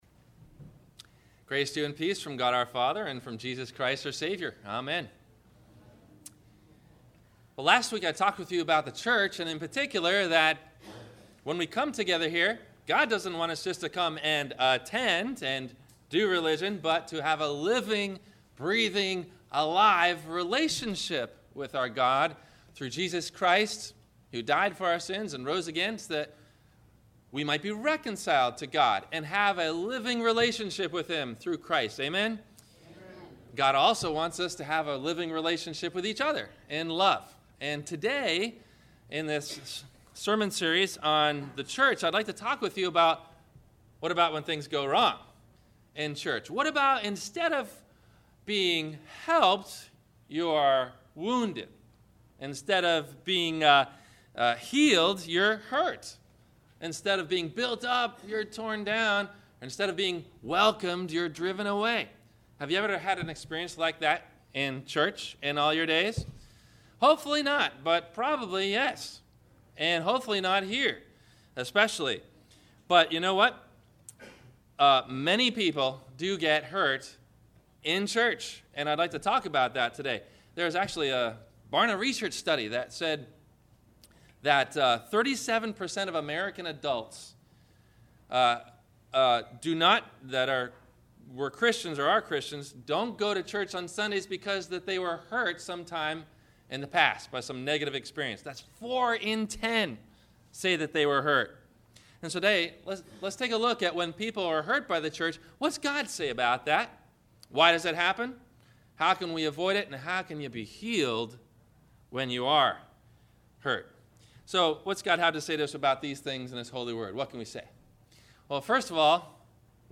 Healing From Bad Church Experiences - Sermon - May 25 2014 - Christ Lutheran Cape Canaveral